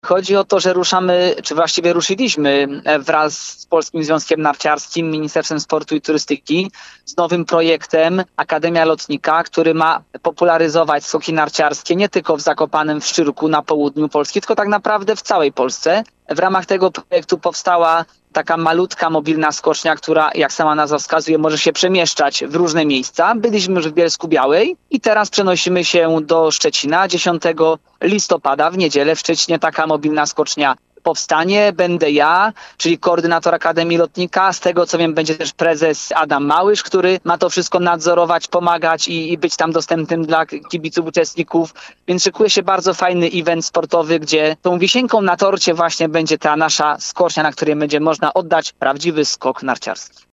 Twoje radio zapytało byłego skoczka narciarskiego Jakuba Kota – o co chodzi?